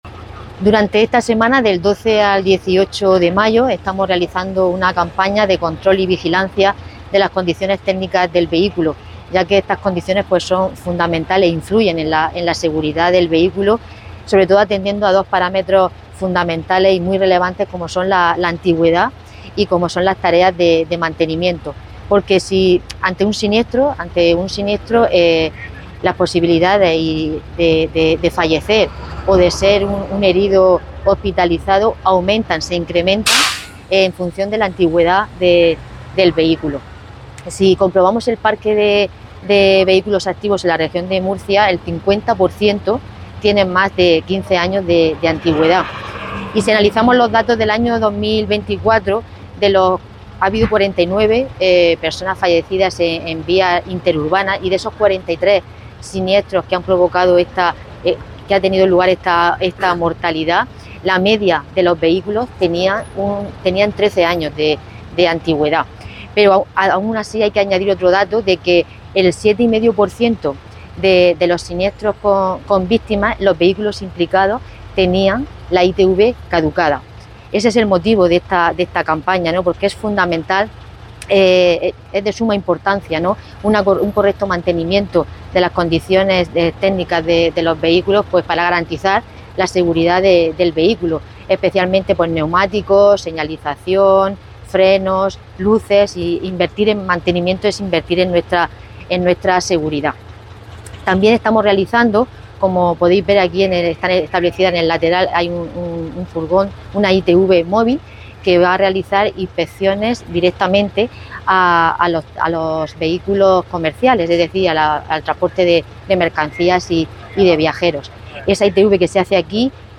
Declaraciones delegada del Gobierno y jefa Provincial de Tráfico